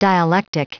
Prononciation du mot dialectic en anglais (fichier audio)
Vous êtes ici : Cours d'anglais > Outils | Audio/Vidéo > Lire un mot à haute voix > Lire le mot dialectic